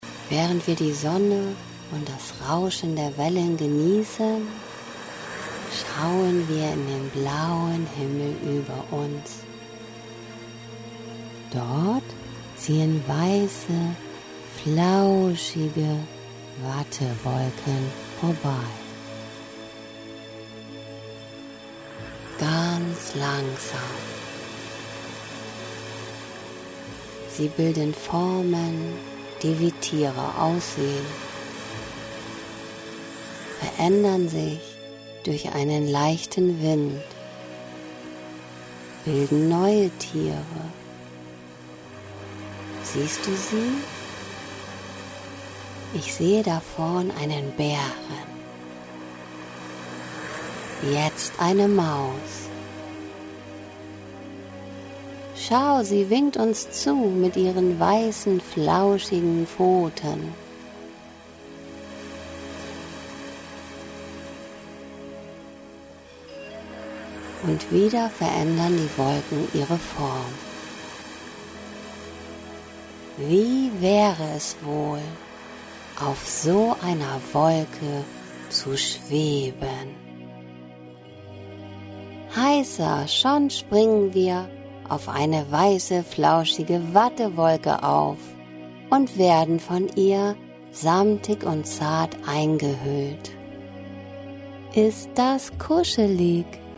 Traummusik spielt währenddessen im Hintergrund und hilft beim Weiterträumen nach dem Ende der Traumreise.